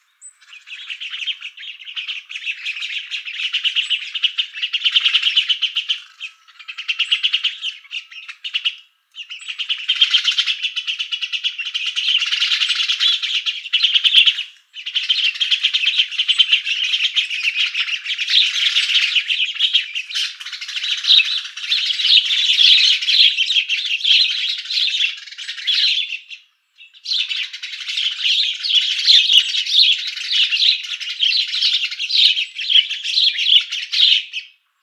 PASSER DOMESTICUS ITALIAE - ITALIAN SPARROW - PASSERA D'ITALIA
E 11° 31' - ALTITUDE: +460 m. - VOCALIZATION TYPE: typical roosting calls. - SEX/AGE: adult males and females - COMMENT: The recording was made before the sunset and the sparrows were still coming to the roost.